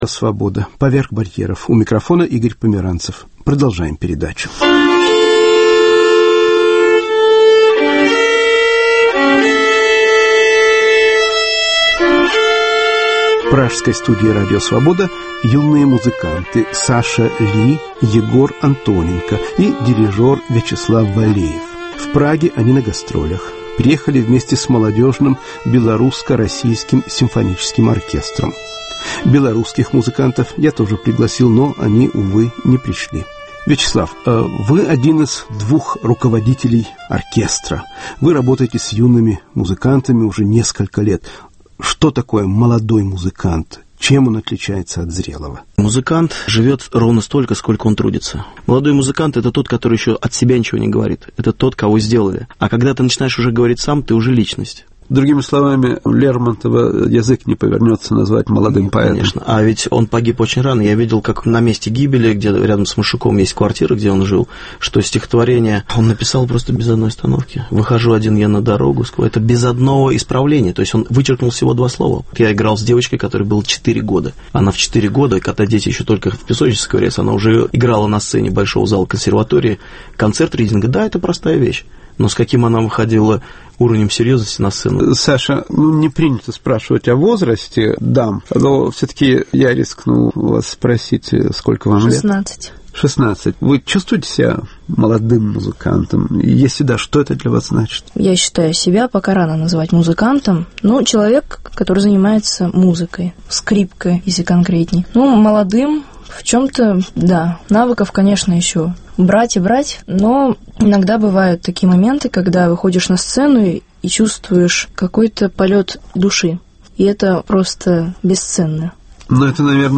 "Молодые музыканты" (разговор в пражской студии "Свободы").